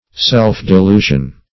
Search Result for " self-delusion" : The Collaborative International Dictionary of English v.0.48: Self-delusion \Self`-de*lu"sion\, n. The act of deluding one's self, or the state of being thus deluded.